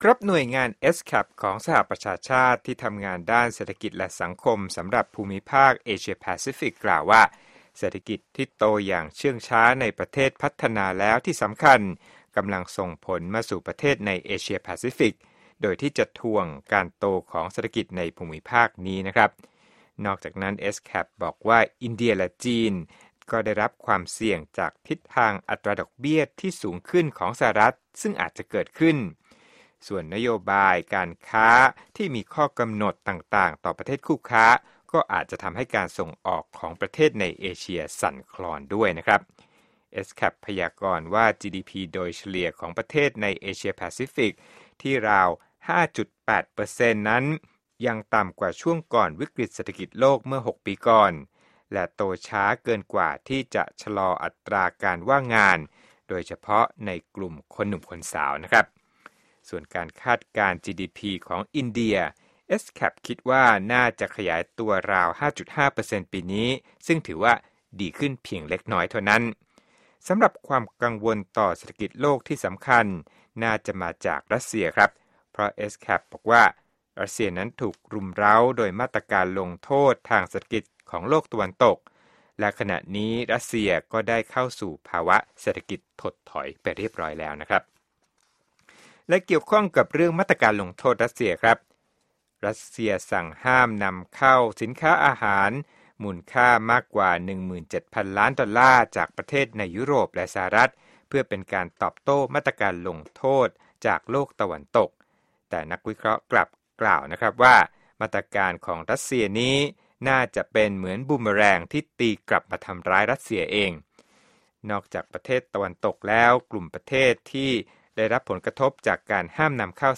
Business News
โปรดติดตามรายละเอียดจากคลิปเรื่องนี้ในรายการข่าวสดสายตรงจากวีโอเอ